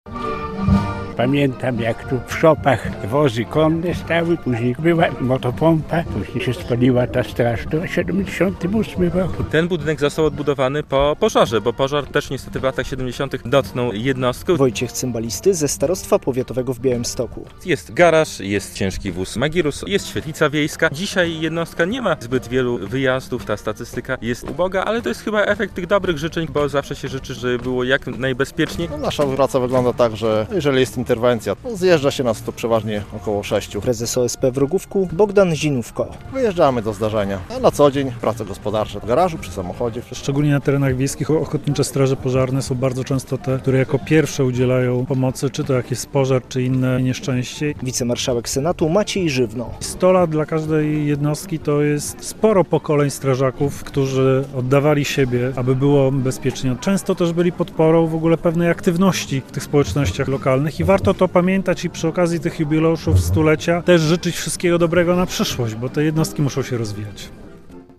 Druhowie z Ochotniczej Straży Pożarnej w Rogówku w gminie Choroszcz świętują stulecie działalności. Przy remizie we wsi odbyła się w sobotę (6.09) uroczysta msza święta.
relacja